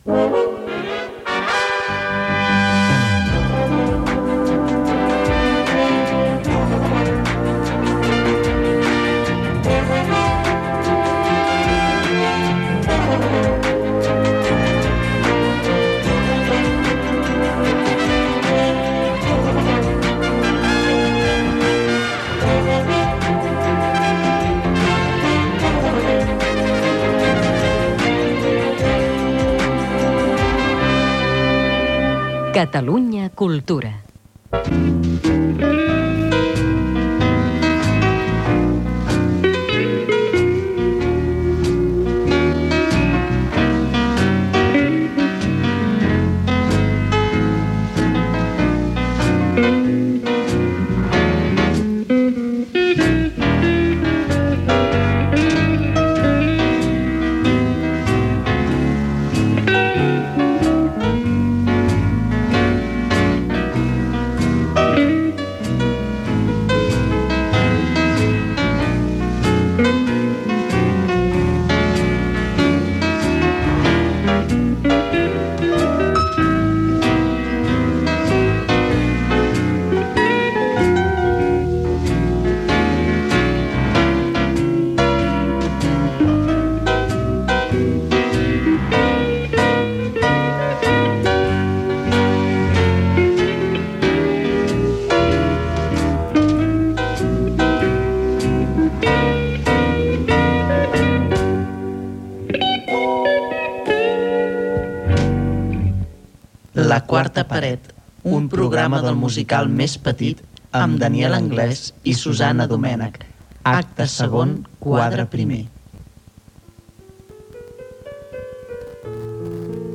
Indicatiu de l'emissora, sintonia del programa, espectacle "Subjectes".